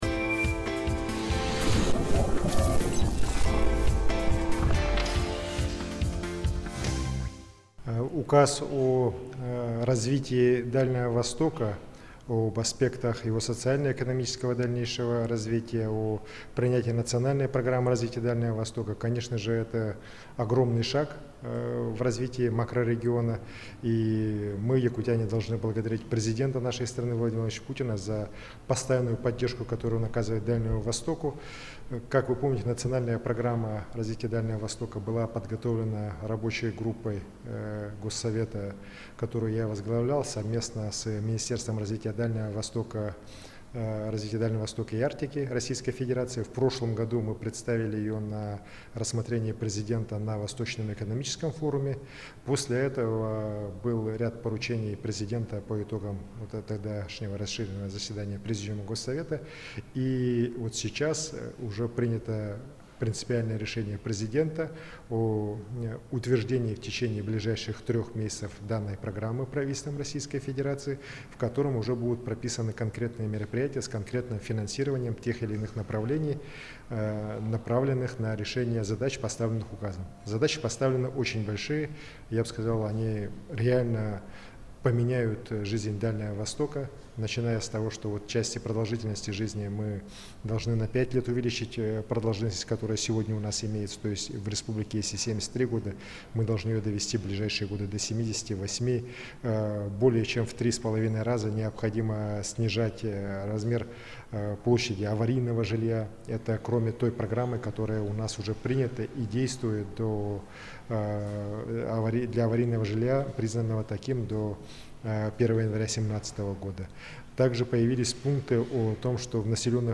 «Это эпохальный документ, над принятием которого мы работали многие годы. Понятно, что в случае его принятия важнейшие объекты республики найдут отражение в Национальной программе развития макрорегиона», — заявил глава республики Айсен Николаев.
NAS_pryamaya-rech-russ.mp3